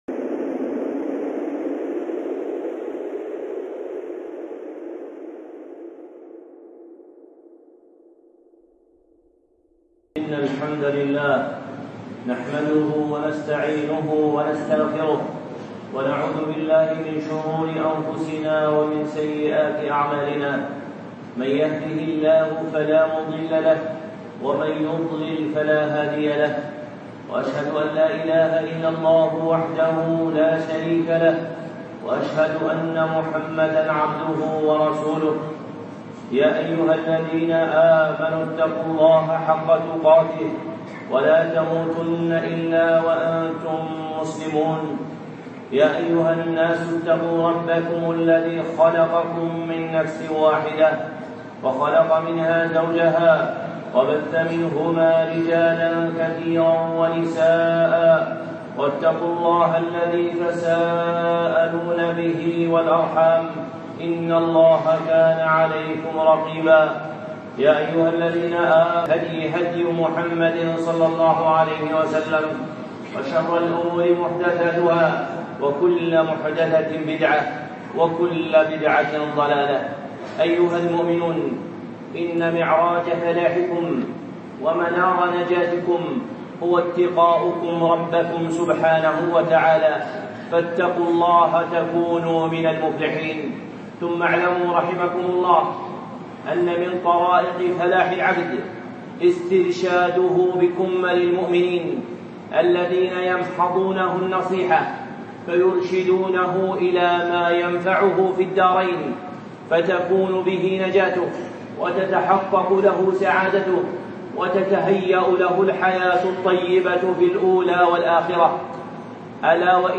خطبة (قل آمنت بالله ثم استقم
الخطب المنبرية